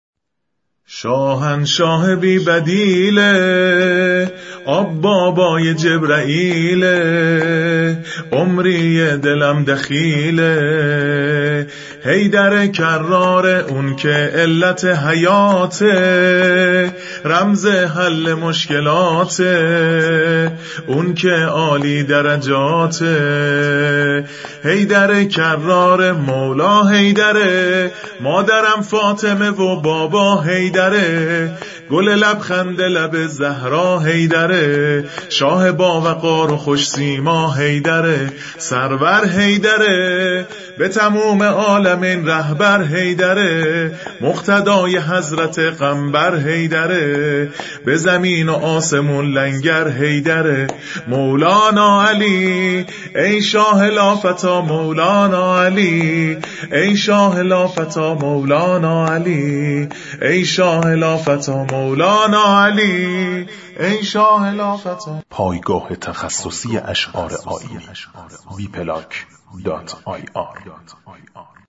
عید غدیر
شور